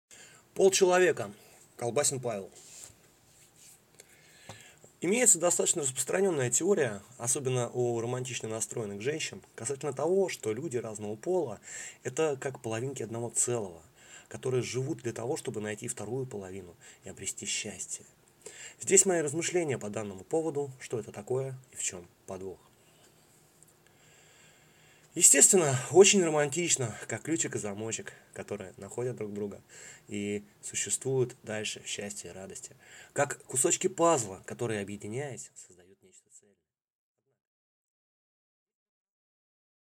Аудиокнига Полчеловека | Библиотека аудиокниг
Прослушать и бесплатно скачать фрагмент аудиокниги